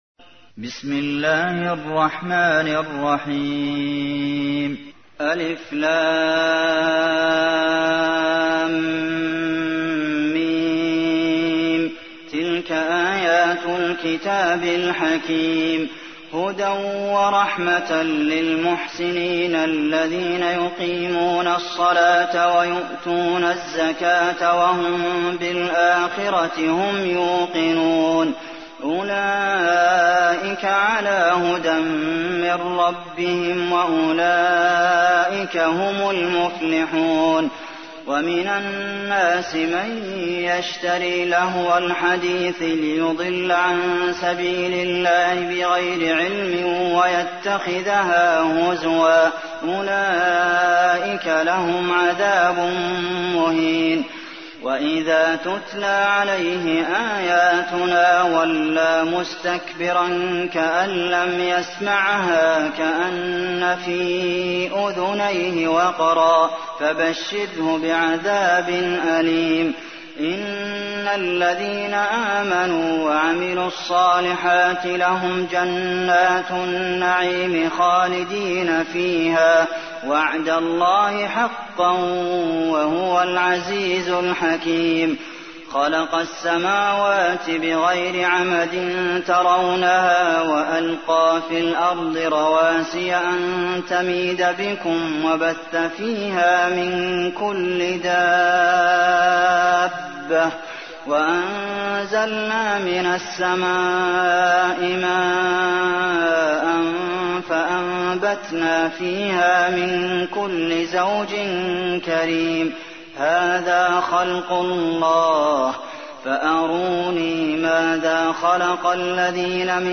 تحميل : 31. سورة لقمان / القارئ عبد المحسن قاسم / القرآن الكريم / موقع يا حسين